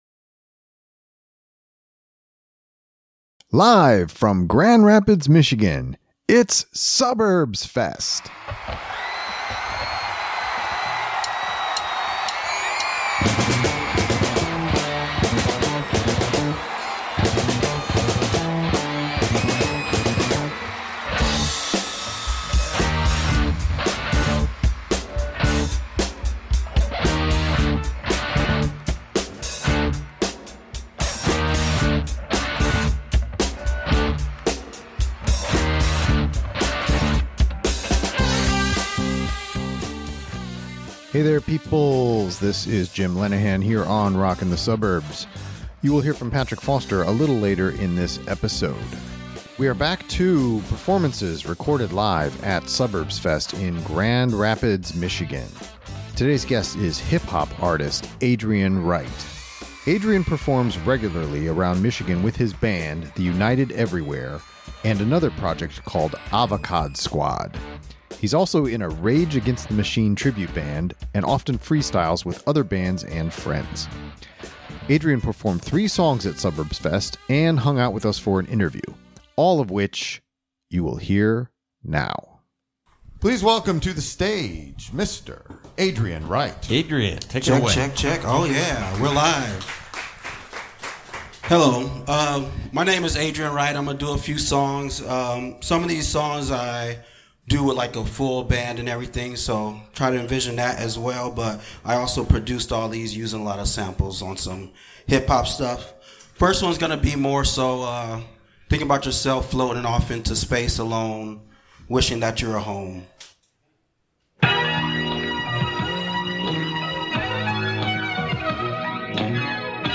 We are coming at you live from Suburbs Fest in Grand Rapids MI.